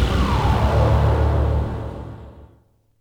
whirling.wav